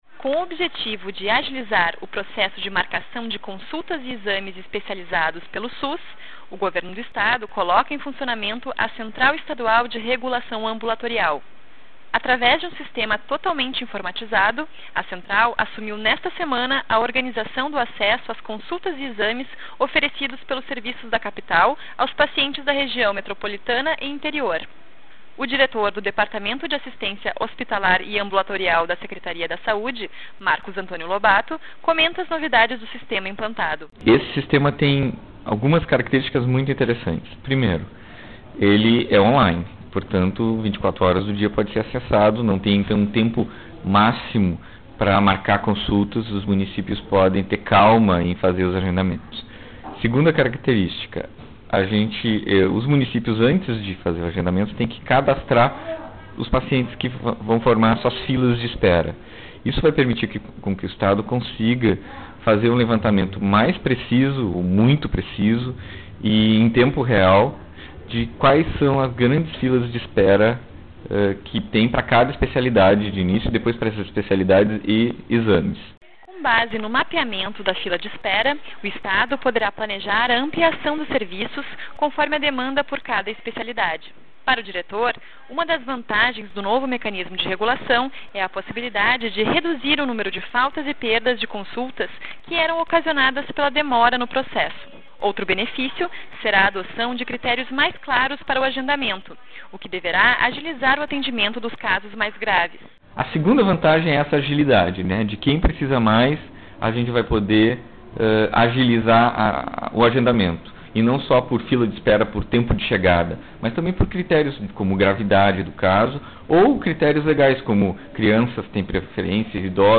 boletim-central-regulacao.mp3